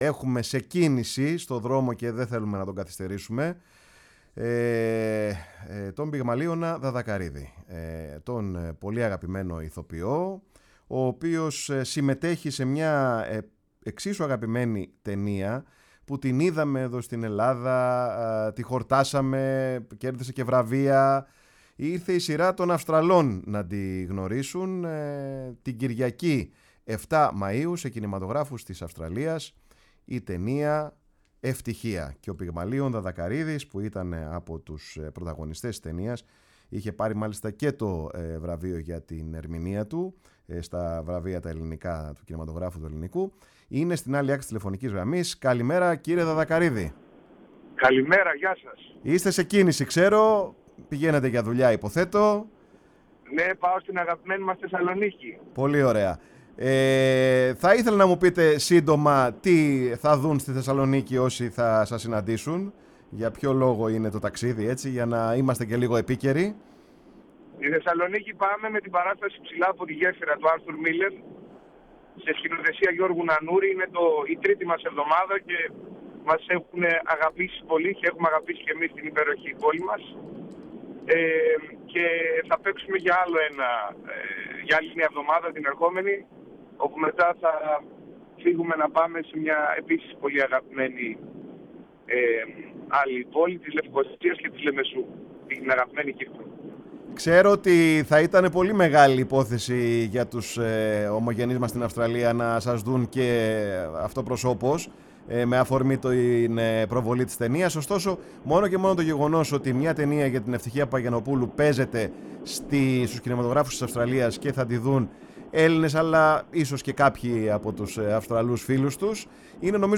Η ΦΩΝΗ ΤΗΣ ΕΛΛΑΔΑΣ Παρε τον Χρονο σου ΕΝΗΜΕΡΩΣΗ Ενημέρωση ΟΜΟΓΕΝΕΙΑ Πολιτισμός ΣΥΝΕΝΤΕΥΞΕΙΣ Συνεντεύξεις ΕΥΤΥΧΙΑ Ευτυχια Παπαγιαννοπουλου Καρυοφυλλια Καραμπετη Ομογενεια Αυστραλια Πυγμαλιων Δαδακαριδης